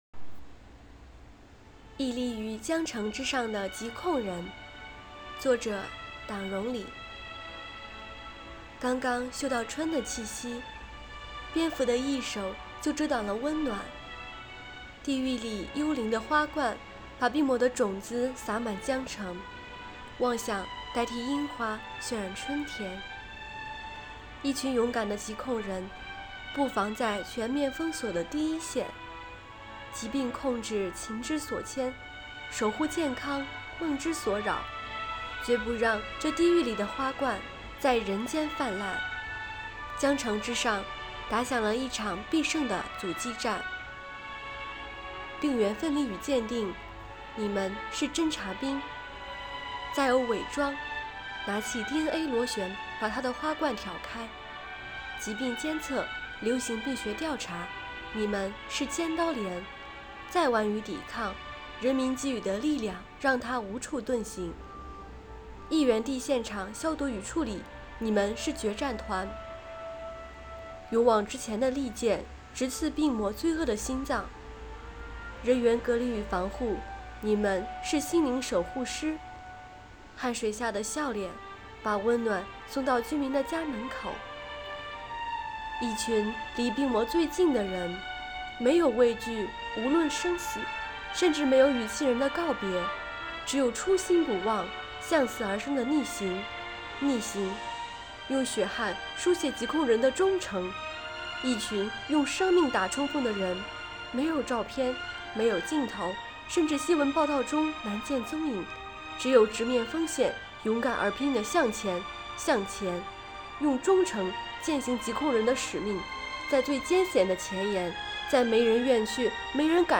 “悦读·新知·致敬”主题朗读比赛|优秀奖